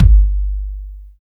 THROB KICK.wav